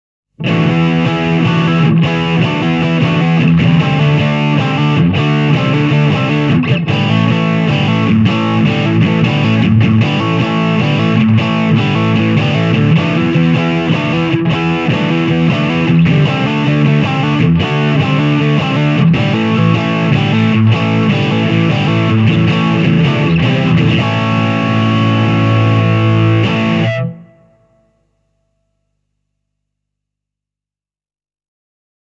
Drive täysille, ja jopa pienen Marshallin puhdas kanava soi stäkin lailla. Tässä on Vox-kitaralla soitettu pätkä: